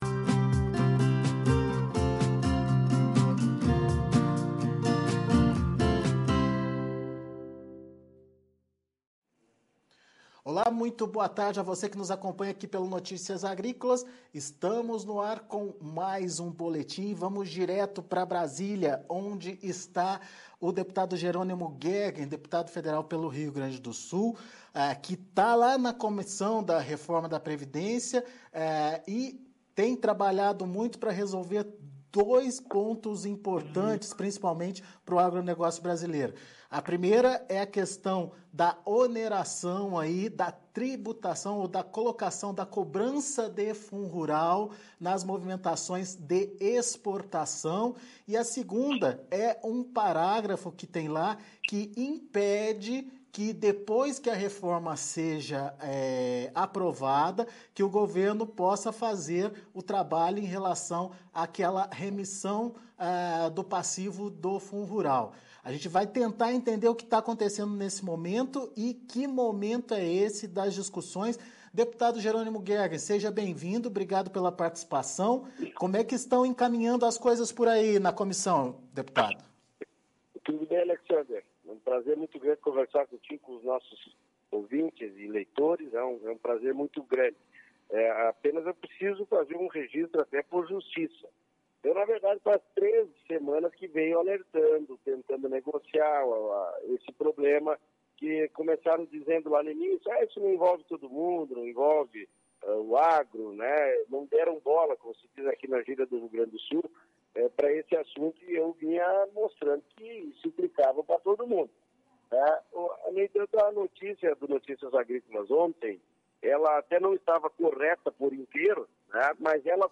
Entrevista com Jerônimo Goergen - Deputado Federal PP-RS sobre os pontos cruciais para o Agro na Reforma da Previdência